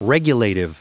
Prononciation du mot regulative en anglais (fichier audio)
Prononciation du mot : regulative